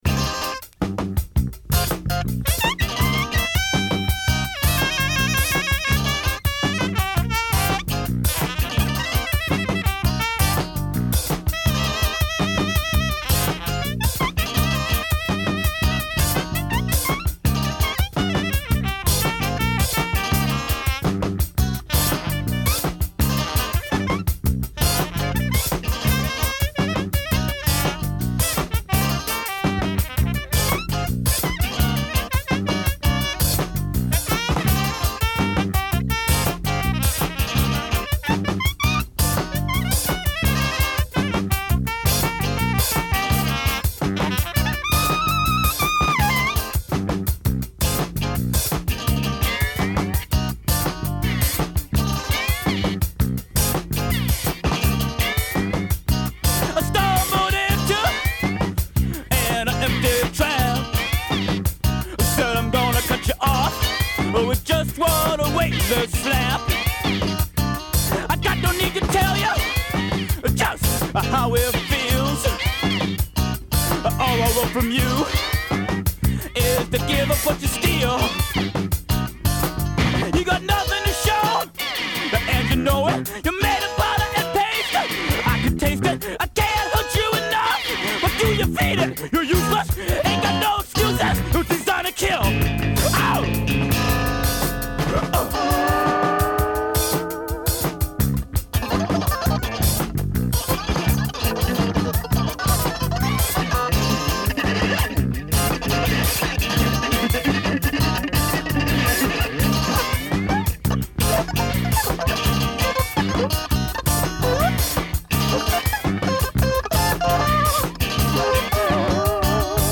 Filed under disco